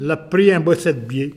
Localisation Petosse
Catégorie Locution